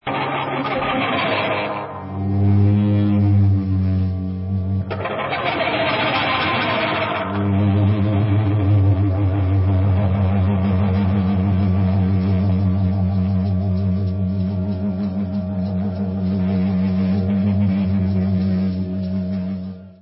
live 2001